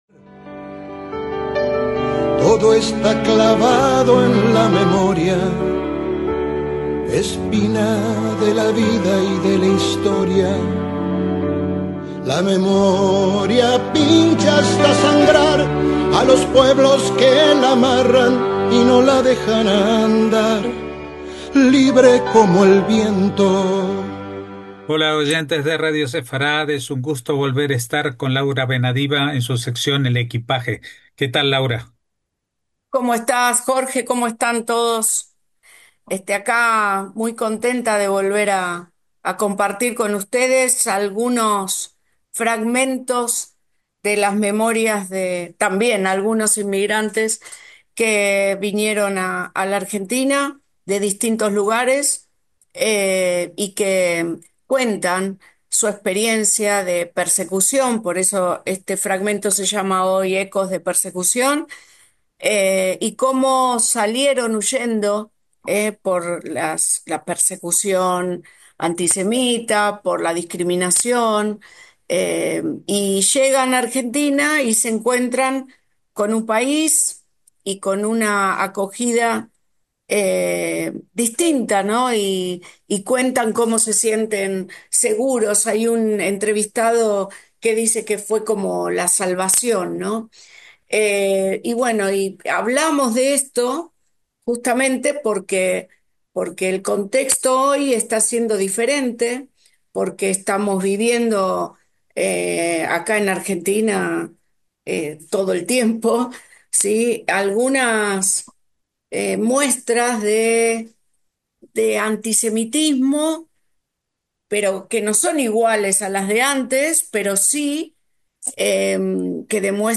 EL EQUIPAJE: HISTORIA ORAL DE EMIGRANTES JUDÍOS